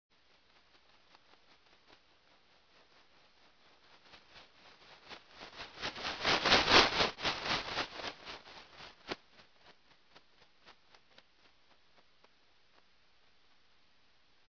На этой странице собраны звуки бабочек и мотыльков – нежные шелесты крыльев, создающие атмосферу летнего сада или лесной поляны.
Шепот крыльев бабочки у микрофона